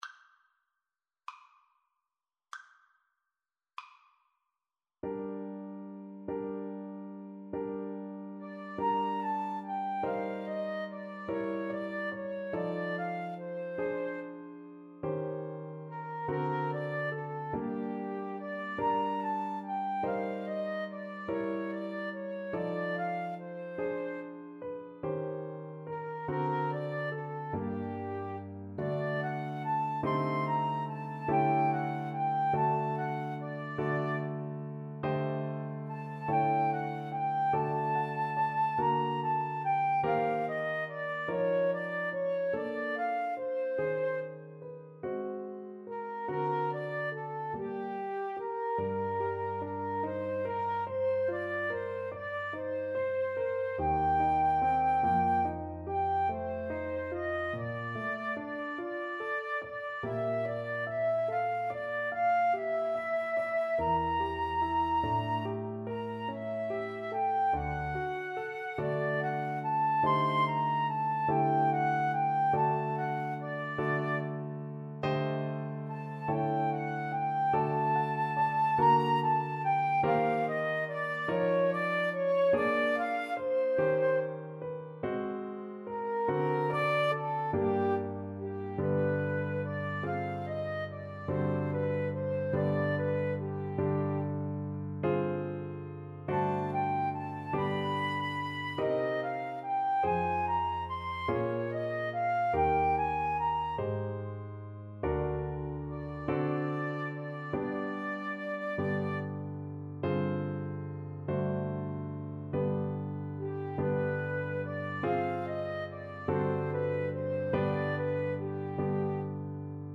. = 48 Andante quasi allegretto
6/8 (View more 6/8 Music)
Classical (View more Classical Flute-Clarinet Duet Music)